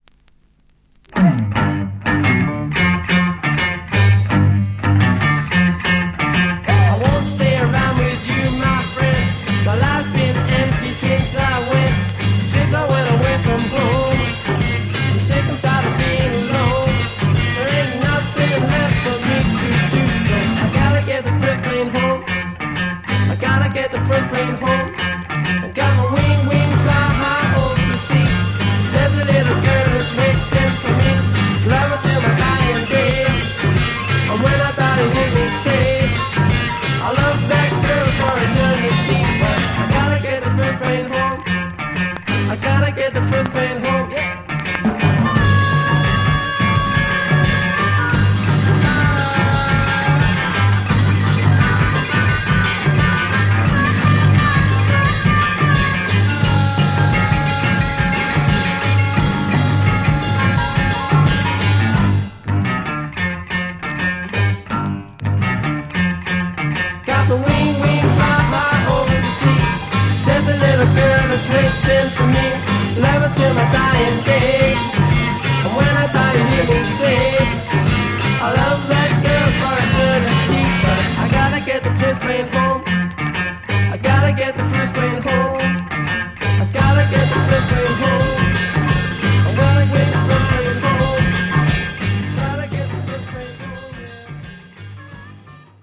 Classic UK Freakbeat garage French EP